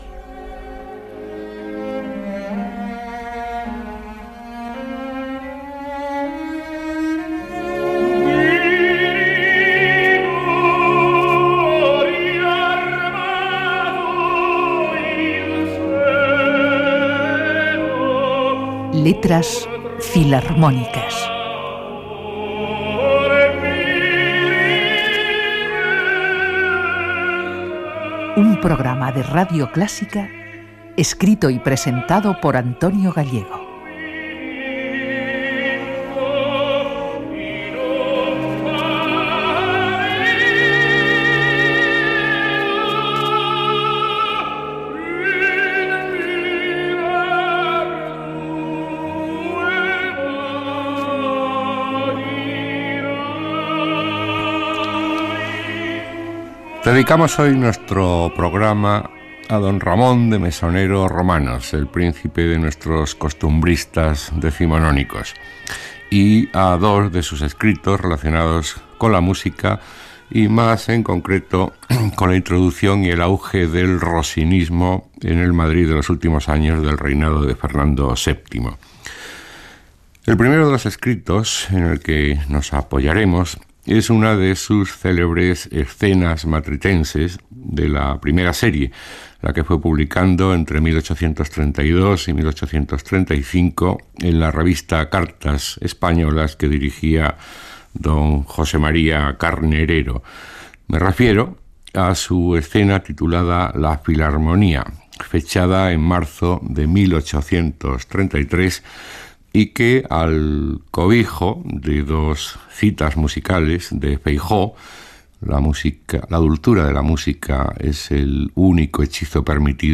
Careta del programa i presentació de l'espai dedicat als escrits de Ramón de Mesonero Romanos sobre Gioachino Rossini
Musical